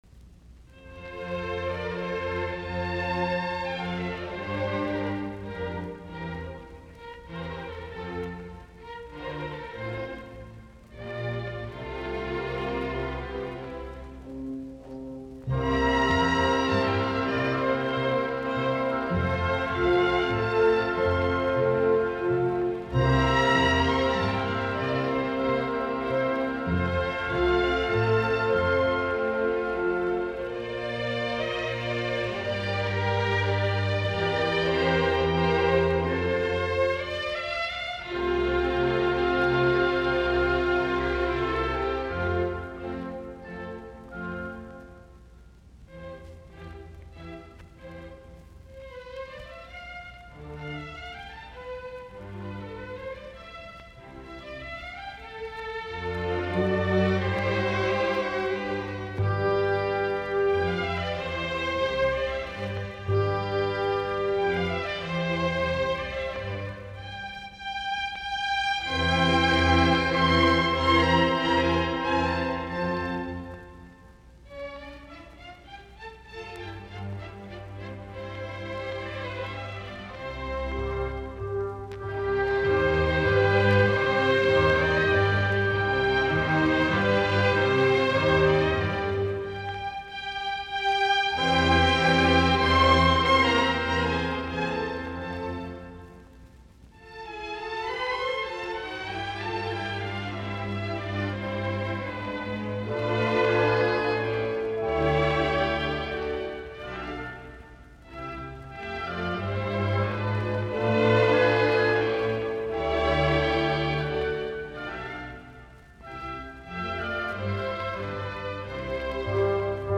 Poco adagio